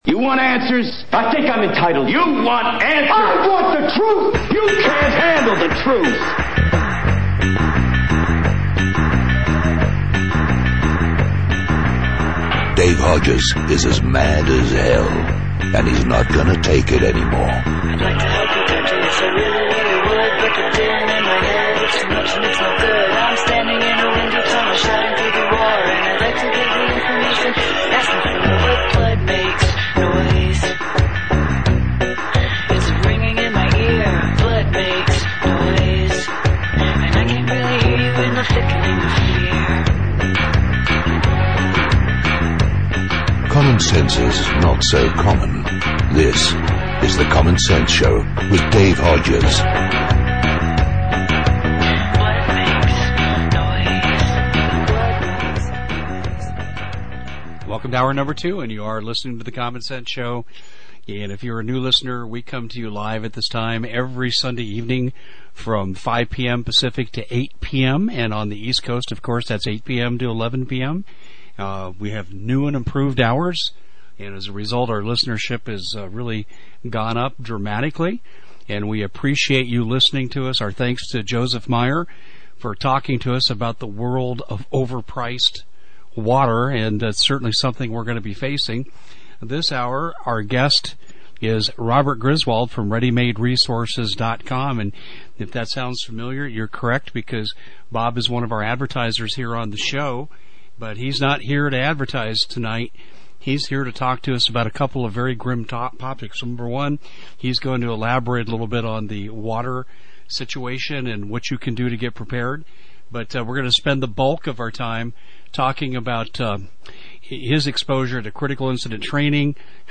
Listen to this riveting interview in you are told how to survive a critical incident. The Interview starts about 12-13 minutes into the recording.